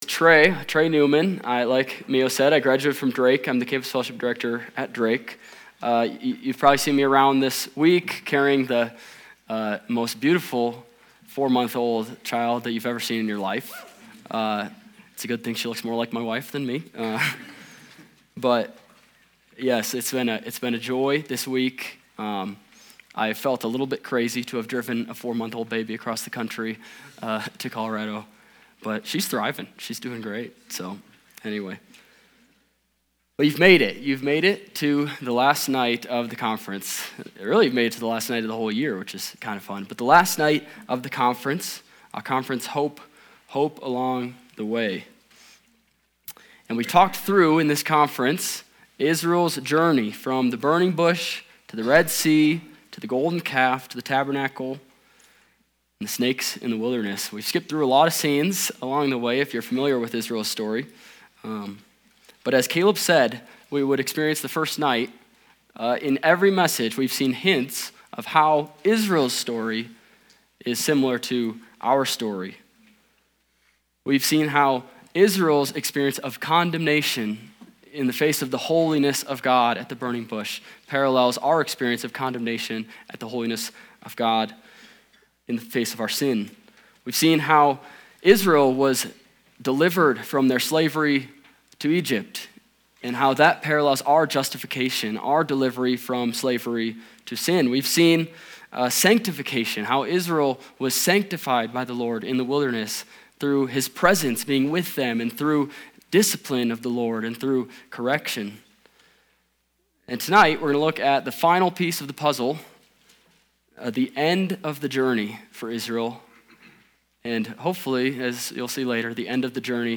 The Promised Land | Winter Retreat 2024 | Hope Along the Way - Campus Fellowship